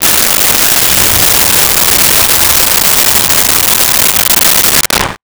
Car Start 01
Car Start 01.wav